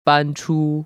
搬出[bānchū]